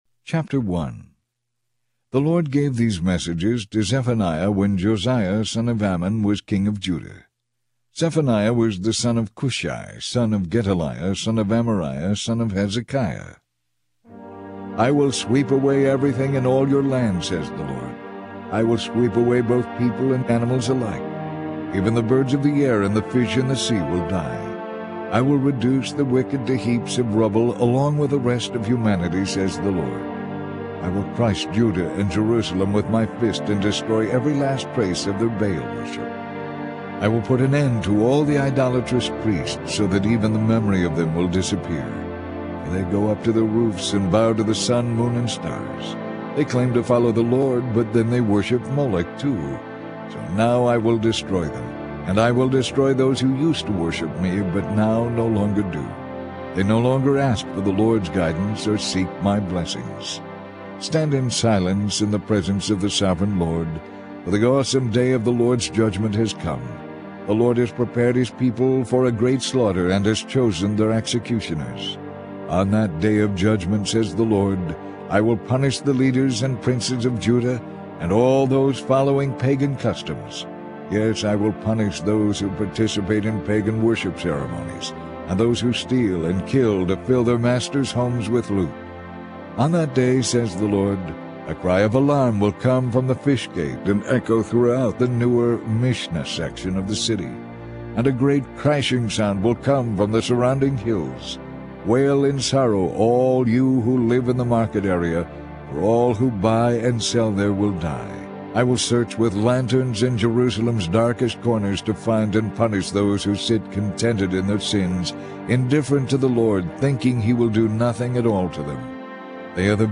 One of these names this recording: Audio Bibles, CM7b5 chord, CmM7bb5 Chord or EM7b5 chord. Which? Audio Bibles